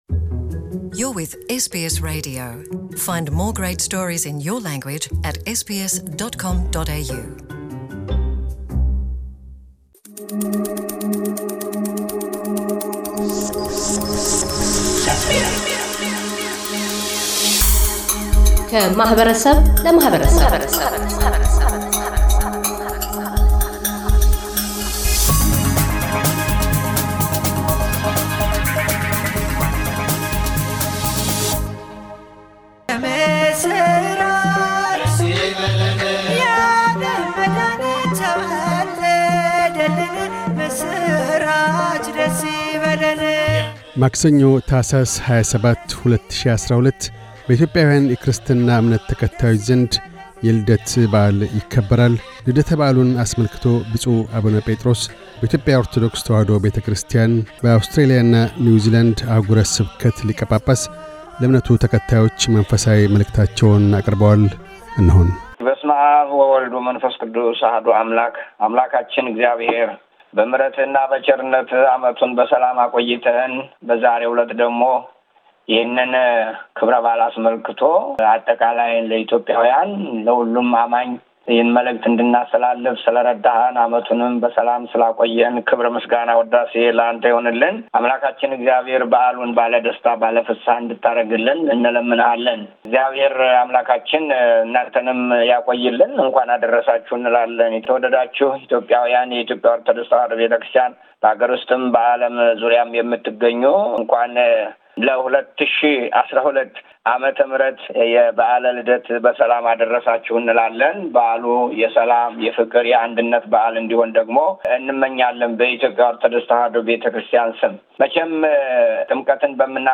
ብፁዕ አቡነ ጴጥሮስ፤ በኢትዮጵያ ኦርቶዶክስ ተዋህዶ ቤተክርስቲያን - በአውስትራሊያና ኒውዚላንድ አኅጉረ ስብከት ሊቀ ጳጳስ፤ የበዓለ ልደት መንፈሳዊ መልዕክታቸውን ለእምነቱ ተከታዮች ያስተላልፋሉ። በአውስትራሊያ ደርሶ ያለውን የእሳት አደጋ አስመልክተውም ኢትዮጵያውያን በያሉበት የልገሳ እጆቻቸውን እንዲዘረጉ ጥሪ ያቀርባሉ።